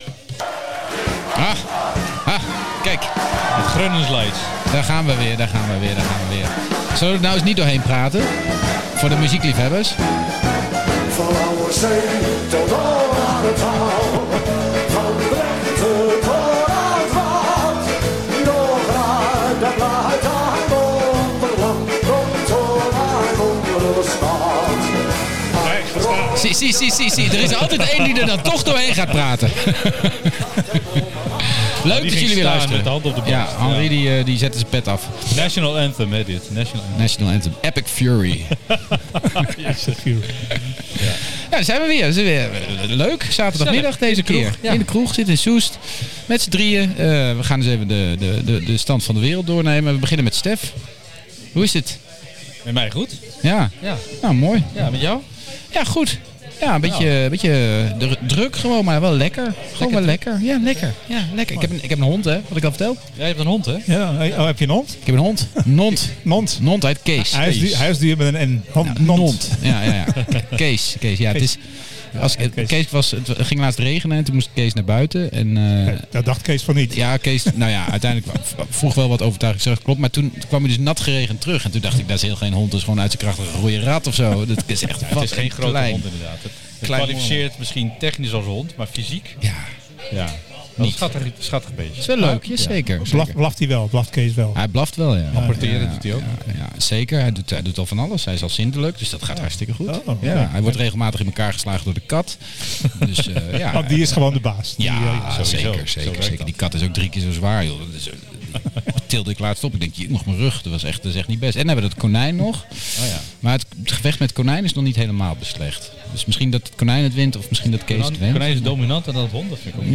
Zaterdagmiddag, borrelpraat in de kroeg.